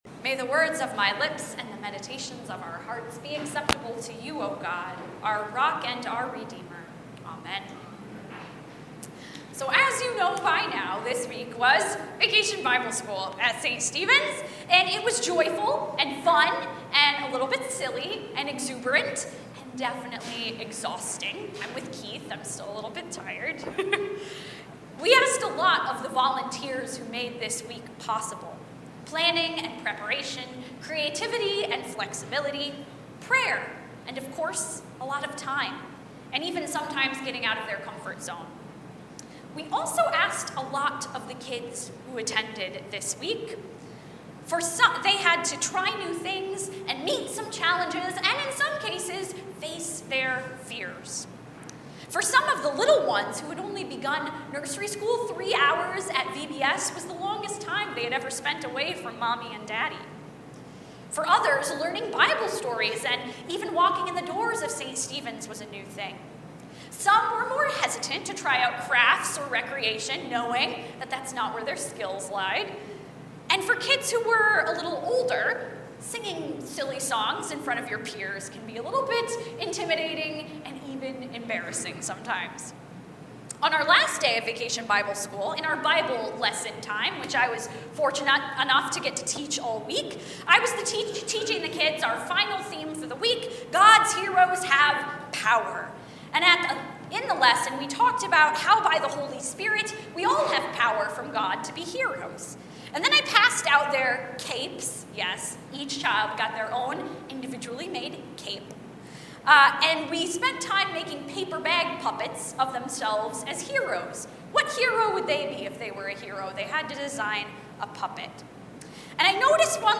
Genesis 37:12-28 Service Type: Sunday Morning 10:30 %todo_render% « You Are Enough!